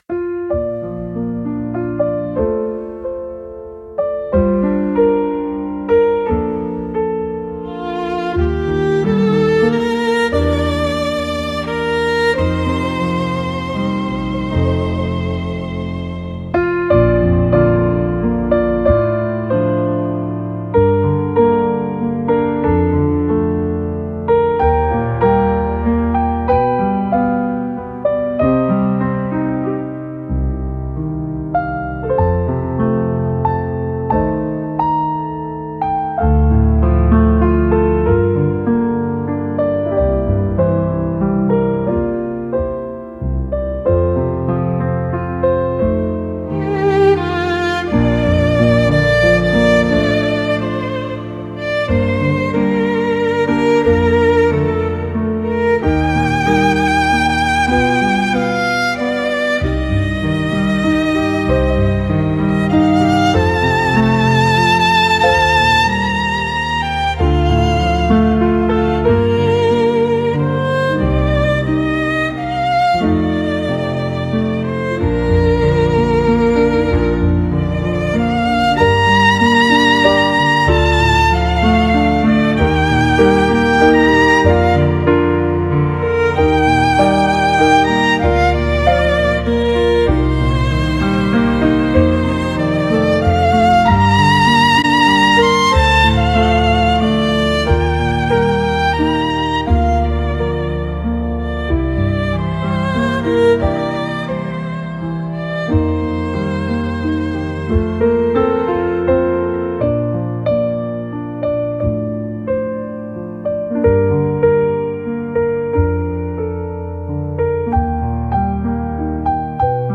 Классическая музыка для